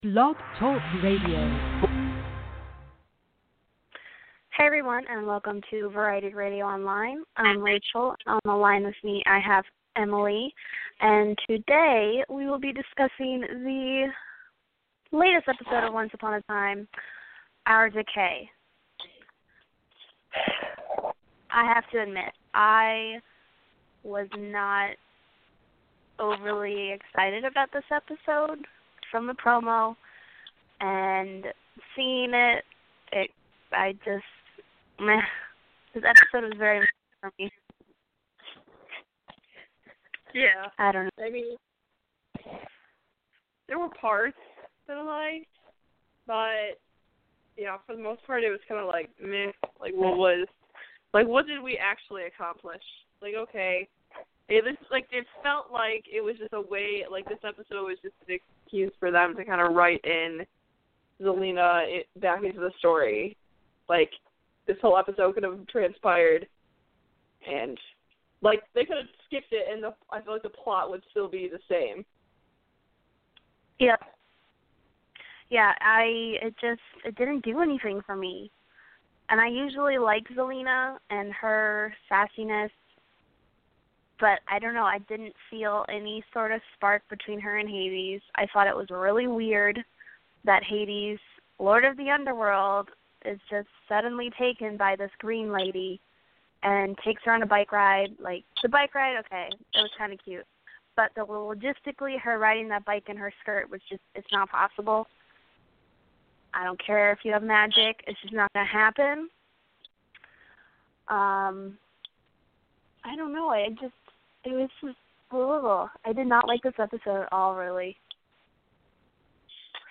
Join our Once Upon a Time hosts as they discuss the highlights of the latest episode of Once Upon a Time - E-mail your thoughts on the episode and the host will read them on air.